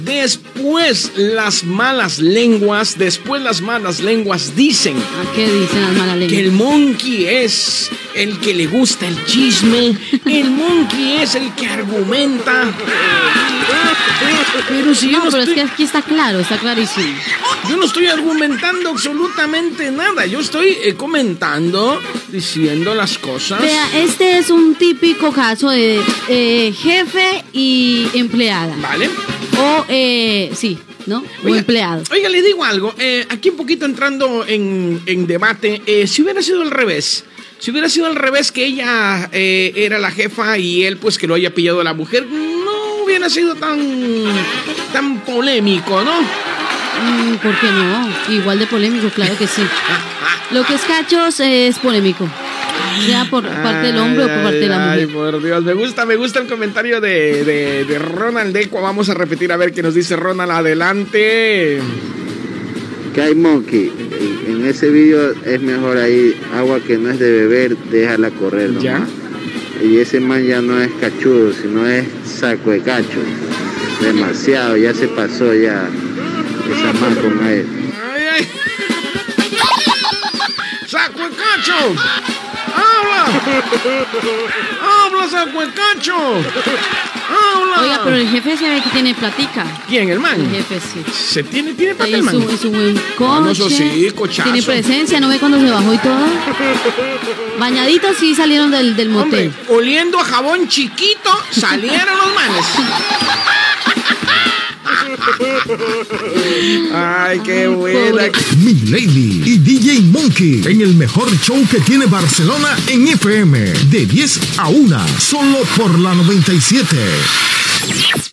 Infidelitats a la feina, amb un comentari d'un oïdor i indicatiu del programa
Entreteniment
FM